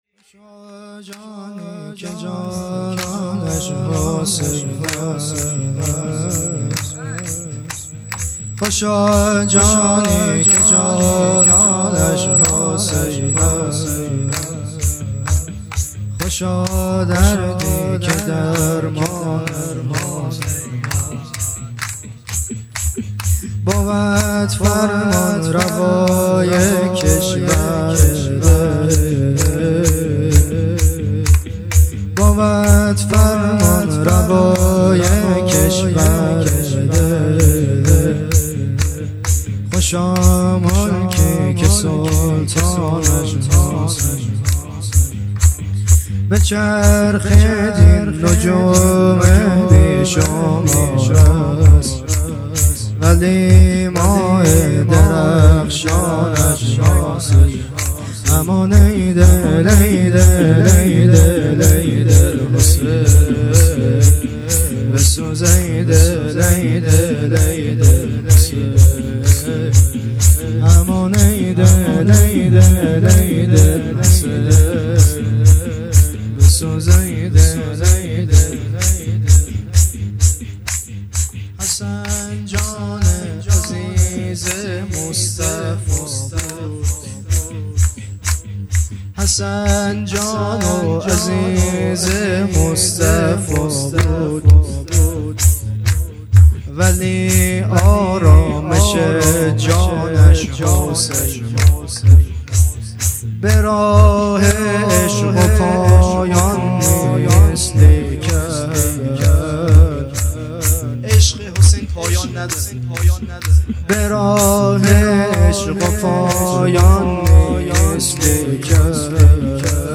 شب لیله الرغائب 28 دی 1402